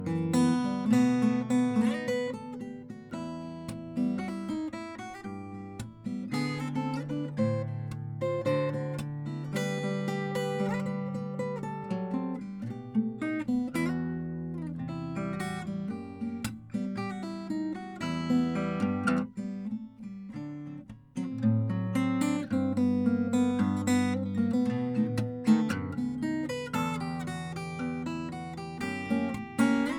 吉他干声试听